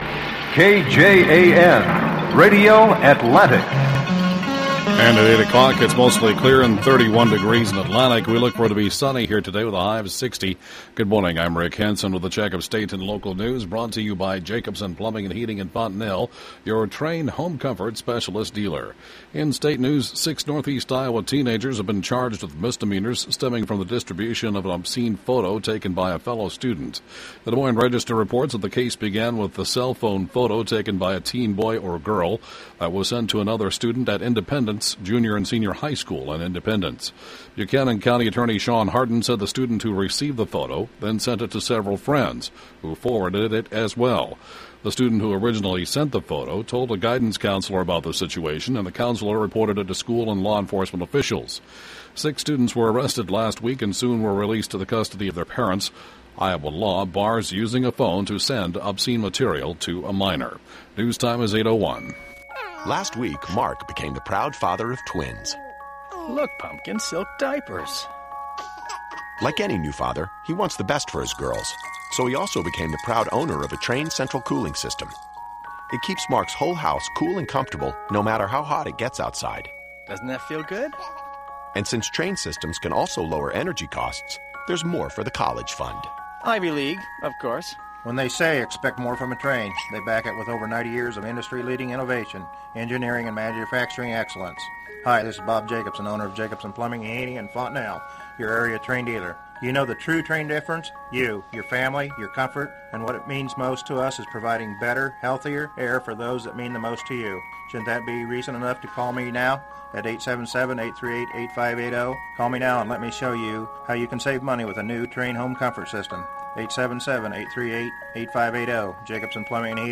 8-a.m. State/Local News (Podcast) 11/20/12